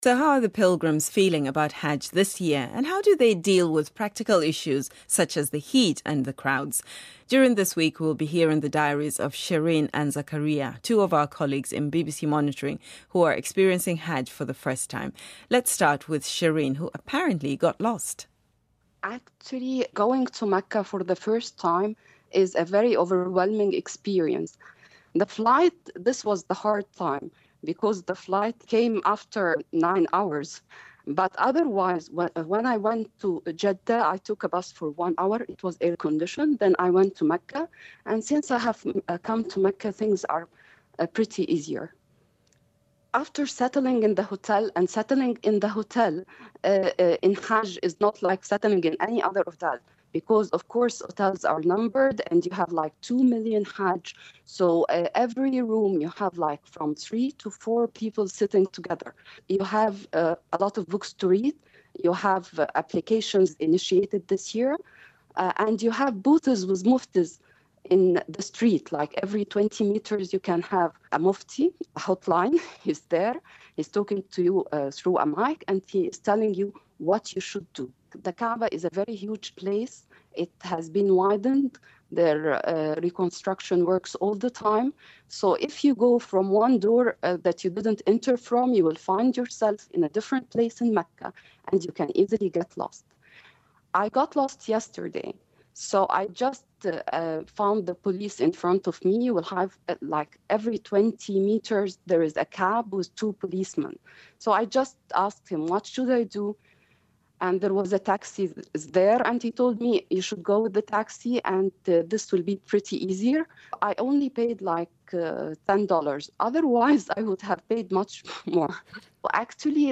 audio postcard from the Hajj